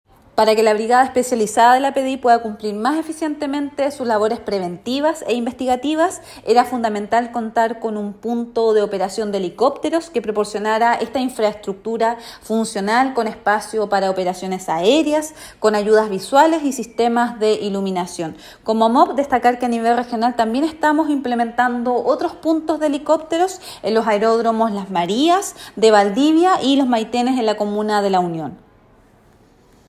Seremi-MOP_-Nuvia-Peralta-punto-posada-helicoptero-PDI.mp3